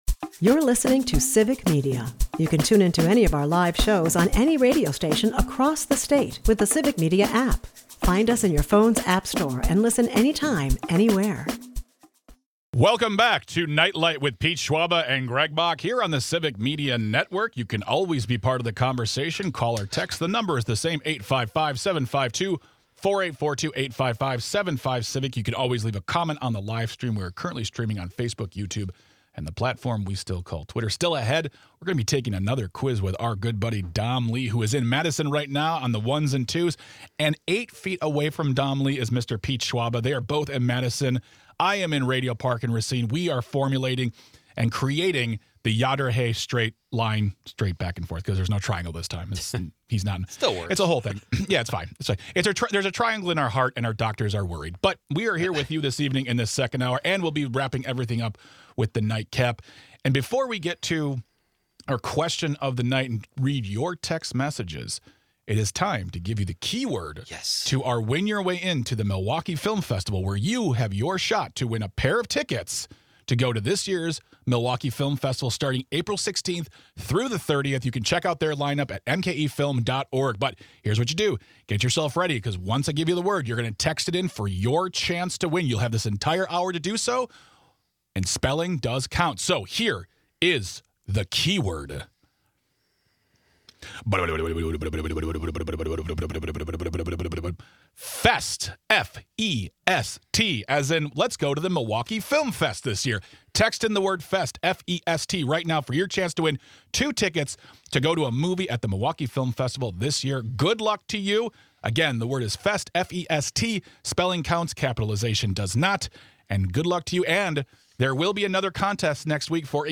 Expect wild guesses, big reactions, and plenty of laughs.